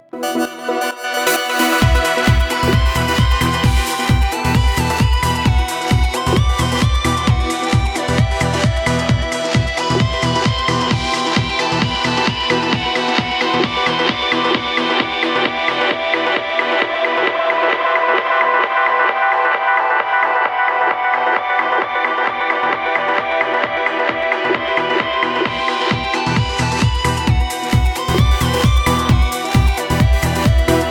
Quick Tip: How to Make an Ultimate Band Pass Filter Delay
You can listen how this device working on fly here:
The EQ will work as resonance band pass filter and purpose of this to filter out the low and high frequencies at the same time.
It will gives nice progressively widen stereo effect the whole effect rack.